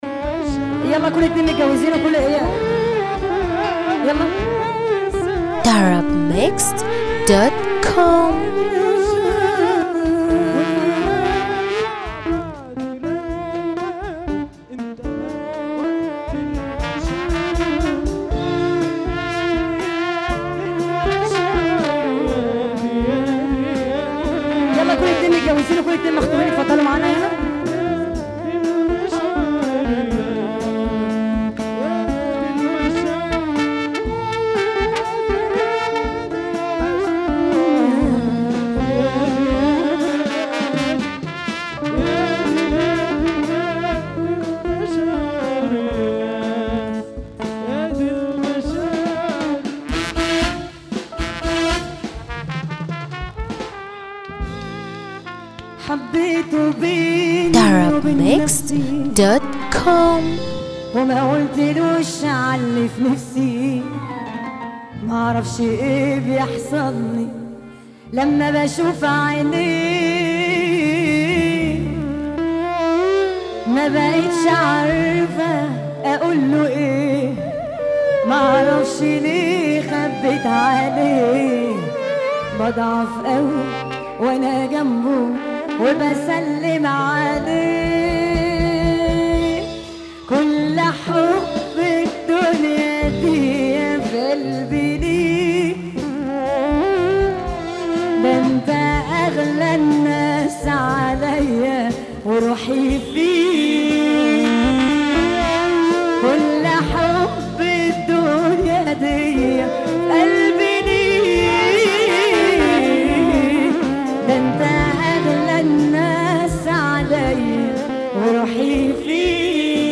موال
بشكل رومانسى جدا